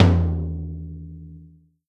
Tom 10.wav